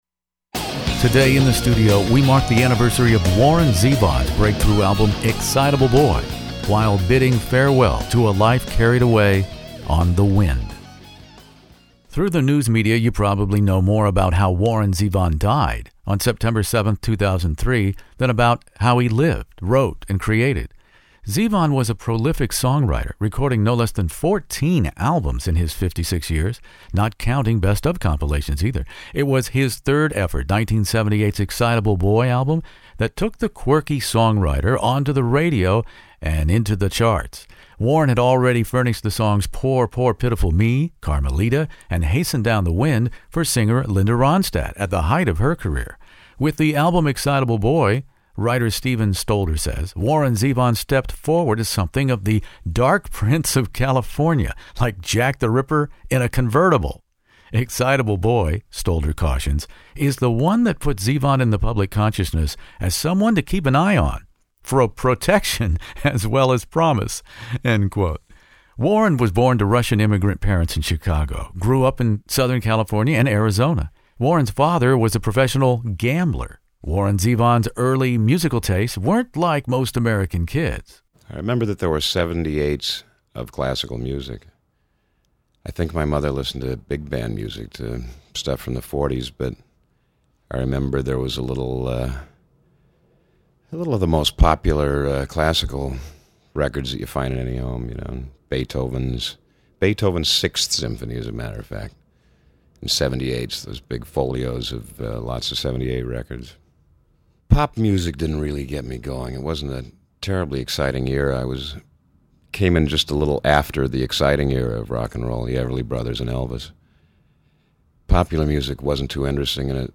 classic rock interview